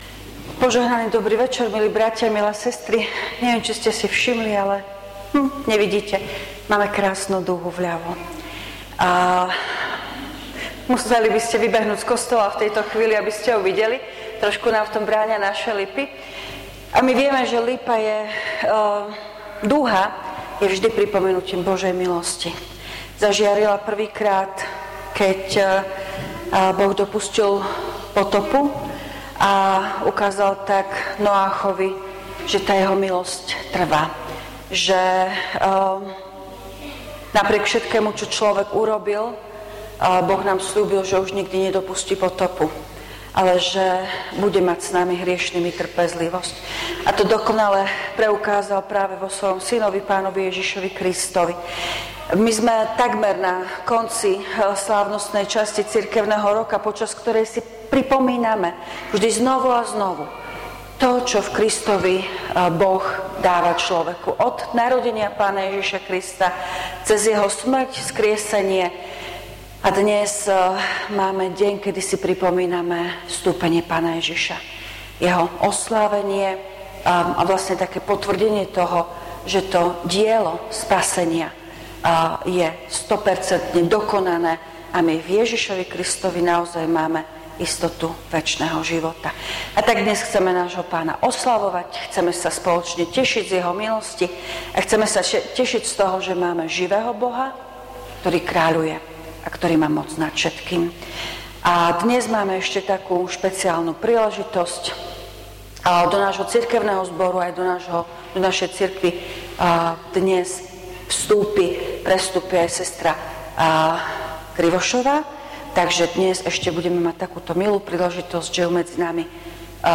Služby Božie – Vstúpenie
V nasledovnom článku si môžete vypočuť zvukový záznam zo služieb Božích – Vstúpenie.